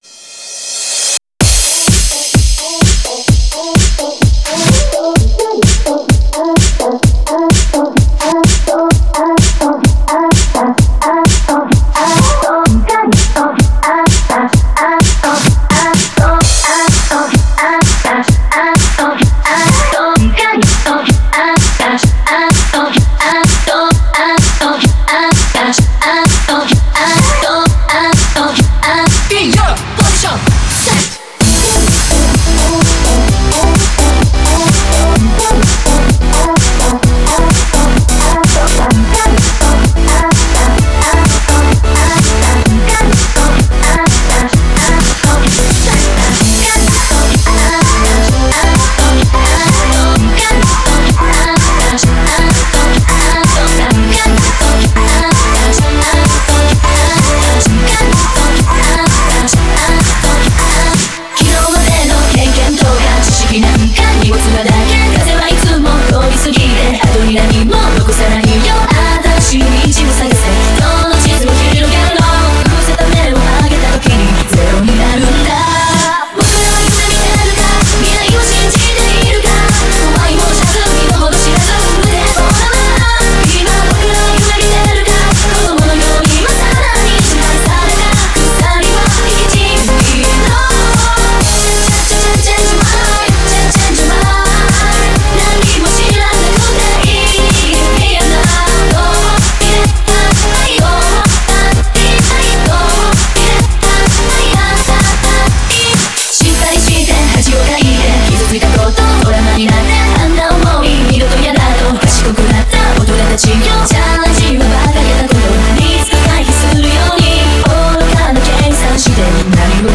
Genre(s): Electro-House